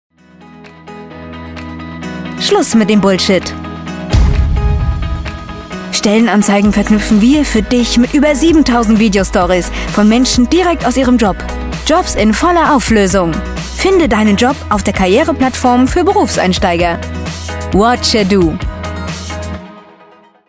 Mezzosopran, Kinder- und Jugendstimme möglich, Stimmalter bis 35 Jahre, junge, warme und angenehme Stimmfarbe erfrischend und dynamisch, sinnlich, seriös, authentisch
Sprechprobe: Sonstiges (Muttersprache):